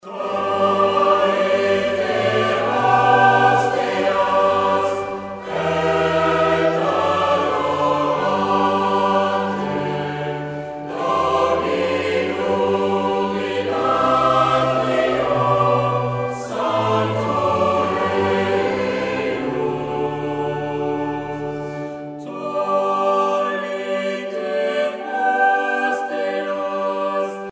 Genre strophique Artiste de l'album Psalette (Maîtrise)
Pièce musicale éditée